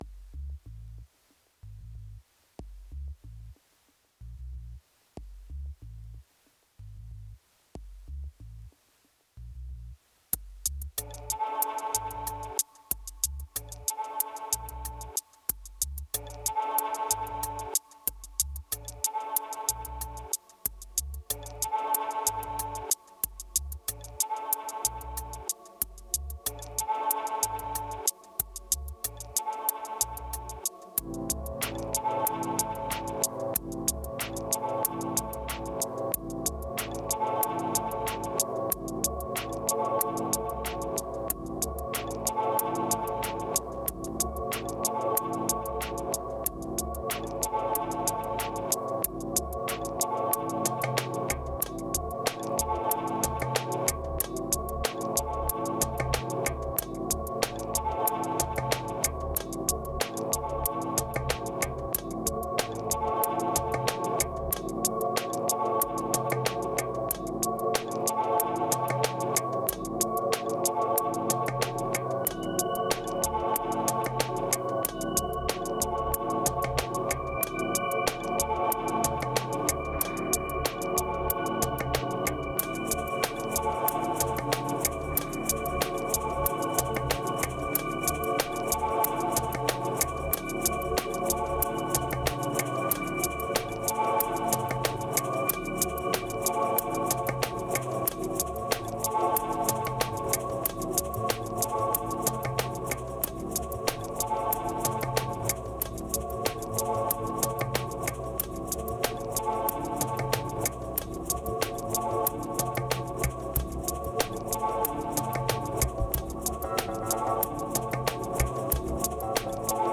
3014📈 - 87%🤔 - 93BPM🔊 - 2017-02-11📅 - 998🌟